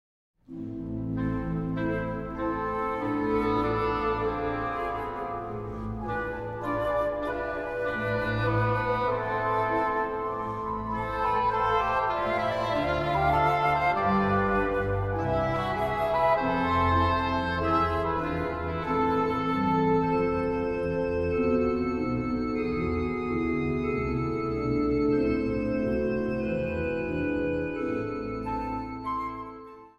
Zang | Kinderkoor
Traditioneel